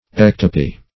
ectopy - definition of ectopy - synonyms, pronunciation, spelling from Free Dictionary Search Result for " ectopy" : The Collaborative International Dictionary of English v.0.48: Ectopy \Ec"to*py\, n. (Med.)